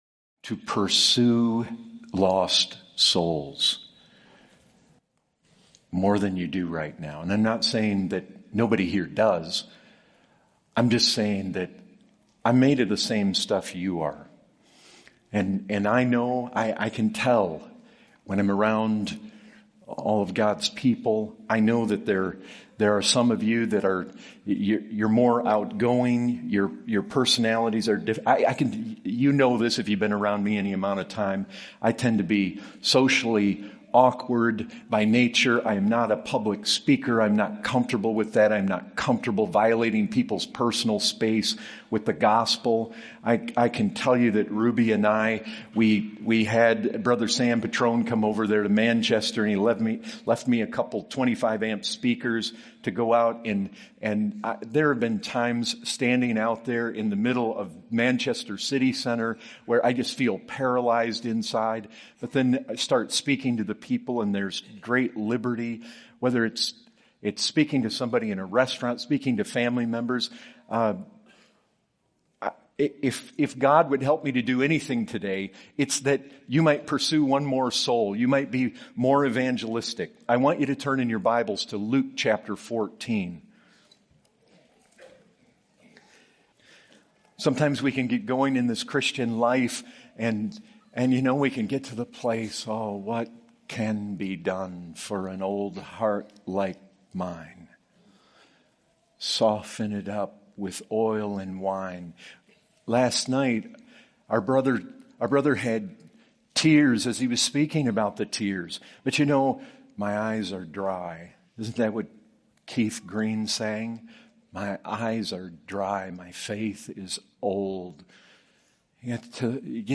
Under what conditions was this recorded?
2022 Fellowship Conference | What is the evangelistic responsibility of the church?